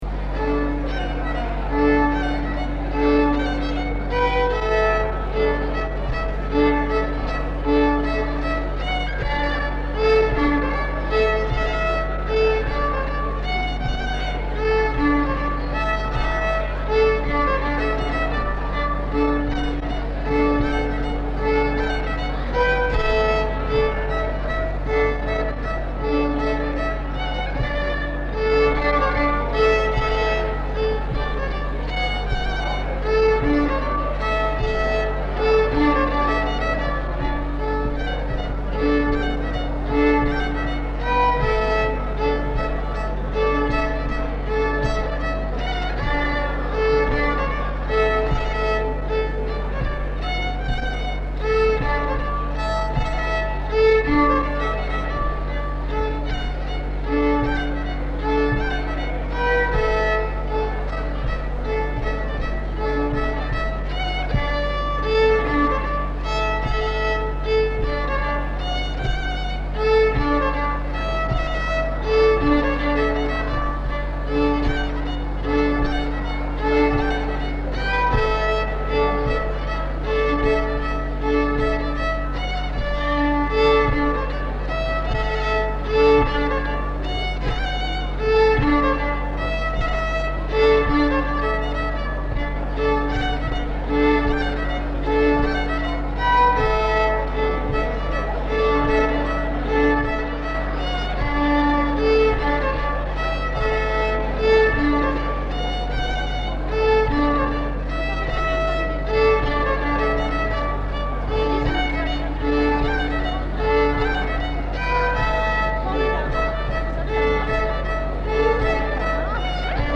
Lieu : Samatan
Genre : morceau instrumental
Instrument de musique : violon
Danse : varsovienne